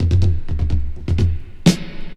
19DR.BREAK.wav